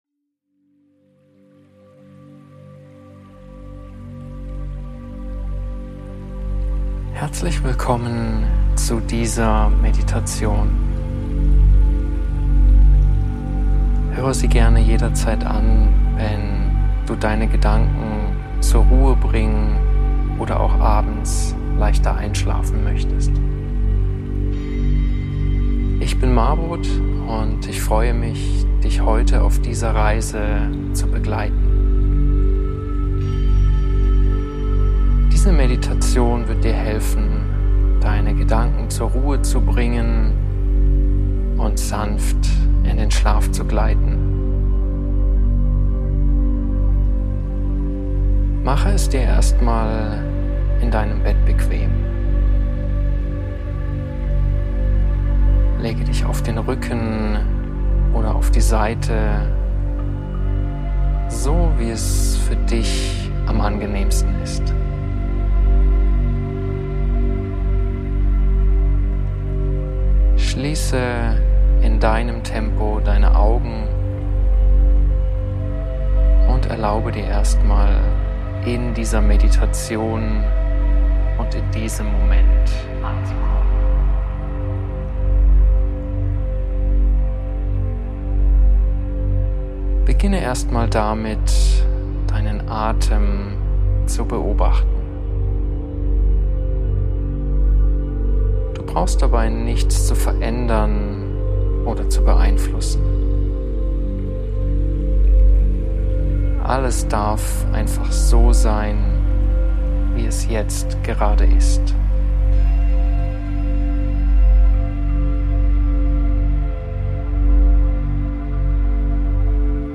In dieser Episode leite ich dich durch eine sanfte Einschlafmeditation, mit der du Schritt für Schritt den Alltag hinter dir lassen kannst.